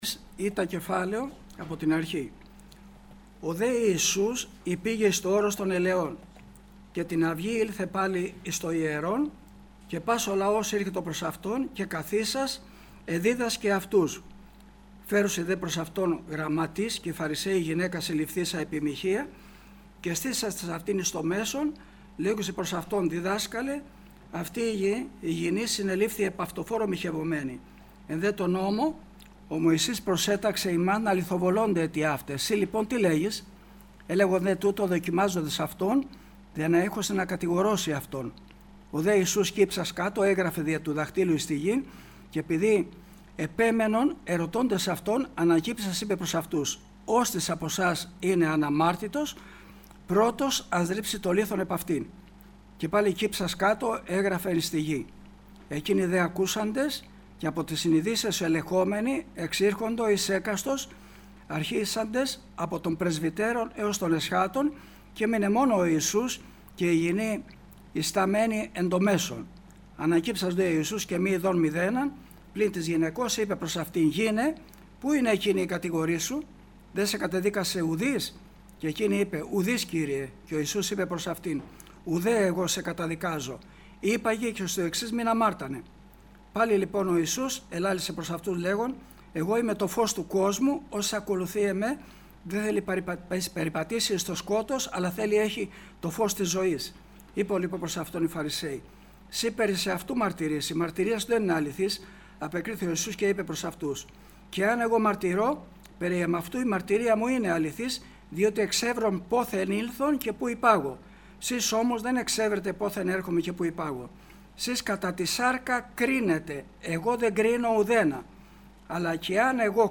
Kηρυγμα Ευαγγελιου